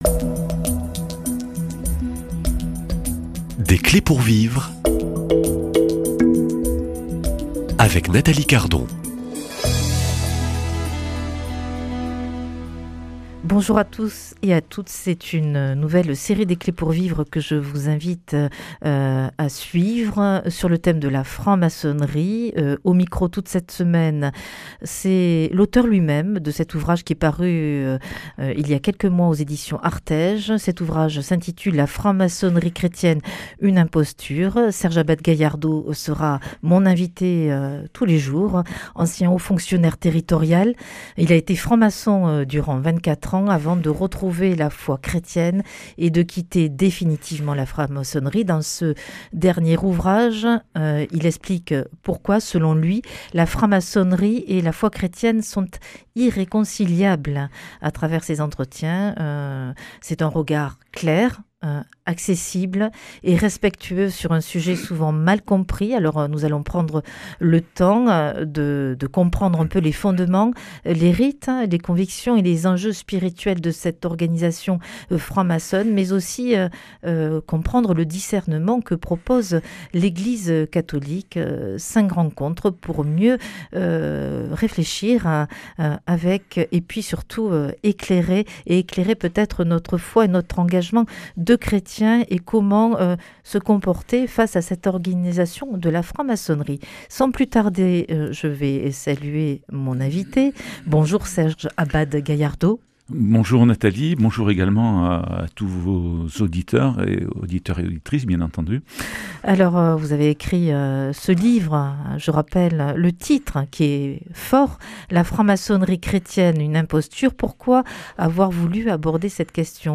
Nous prendrons le temps tout au long de ces 5 entretiens de comprendre les fondements, les rites, les convictions et les enjeux de cette organisation, mais aussi le discernement que propose l’Eglise.